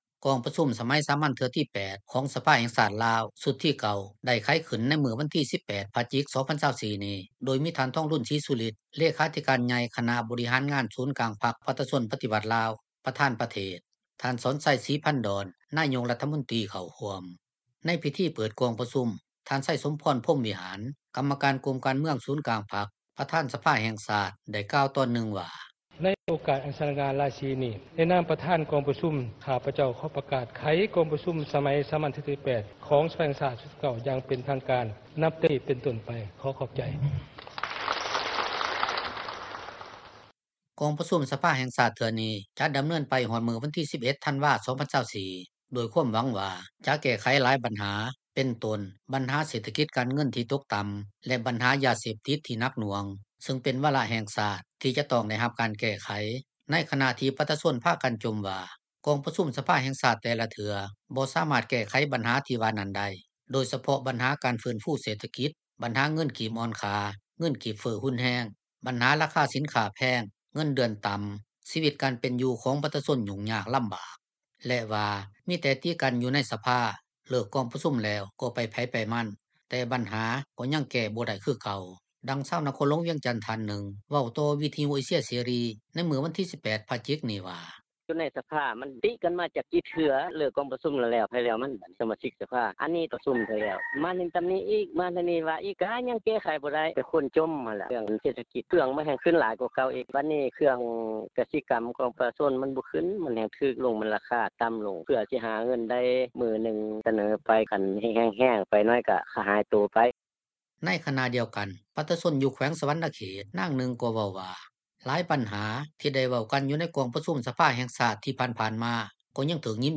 ສະພາແຫ່ງຊາດຊຸດທີ 9 ໄຂຂຶ້ນມື້ນີ້ ໂດຍຫວັງທີ່ຈະແກ້ໄຂບັນຫາເສດຖະກິດ – ຂ່າວລາວ ວິທຍຸເອເຊັຽເສຣີ ພາສາລາວ